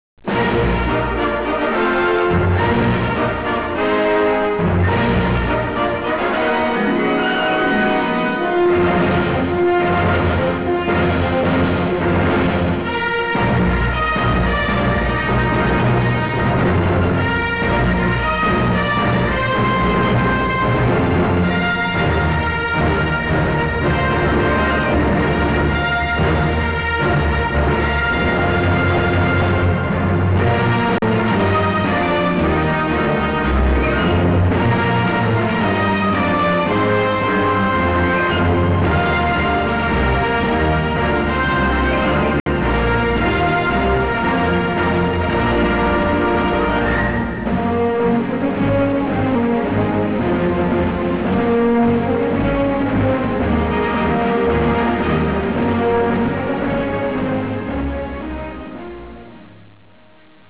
Original track music
Marcia